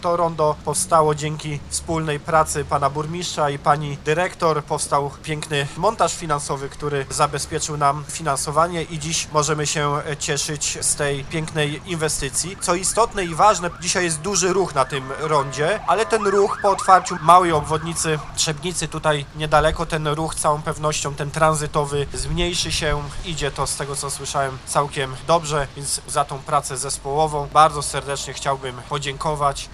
Przebudowa newralgicznego skrzyżowania była możliwa dzięki współpracy zarządu gminy Trzebnica z GDDKiA, co podkreślił poseł na Sejm – Paweł Hreniak.